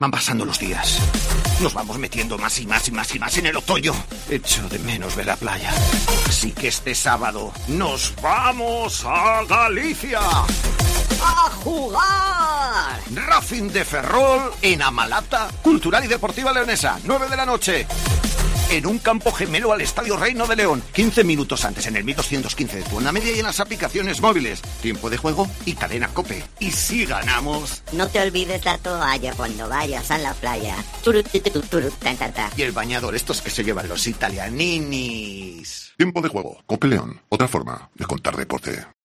Escucha la cuña promocional del partido Racing de Ferrol-Cultural el día 23-10-21 a las 21:00 h en el 1.215 OM